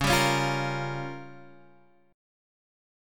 C#6b5 chord